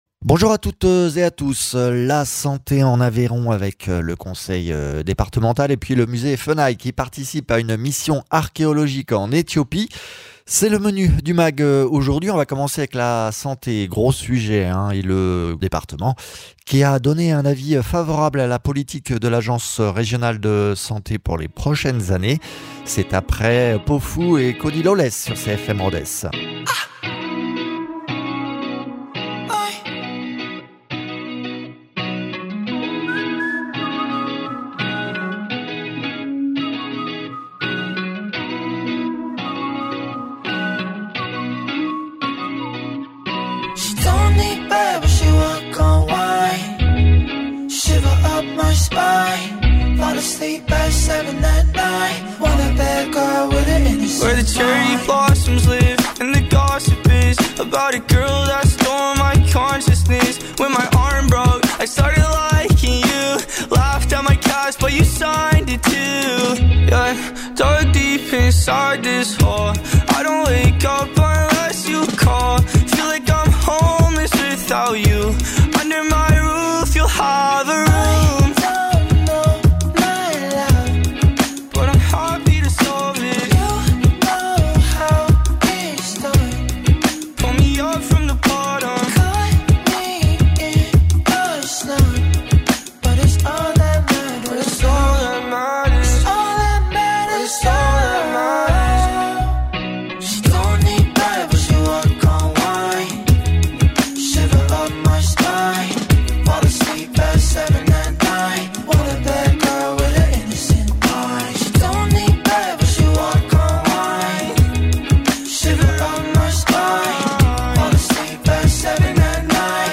Invité(s) : Arnaud Viala, Président du conseil départemental de l’Aveyron